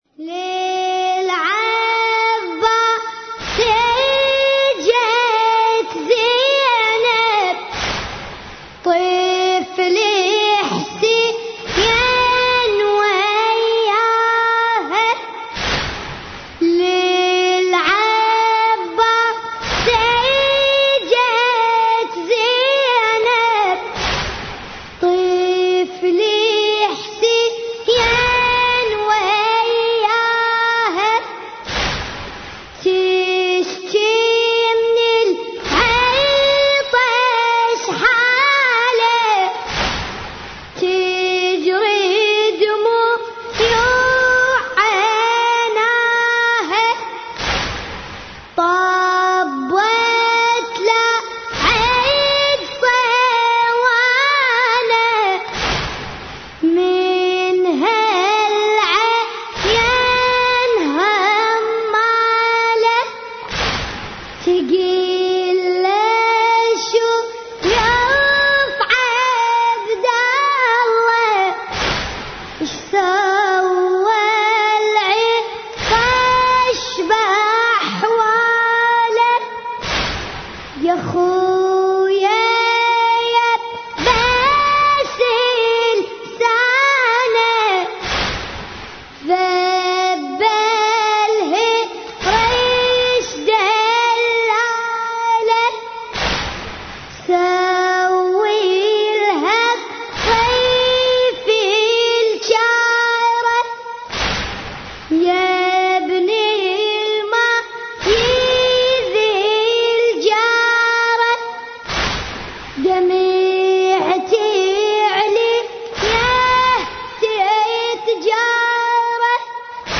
شوط كربلائي
مراثي الامام الحسين (ع)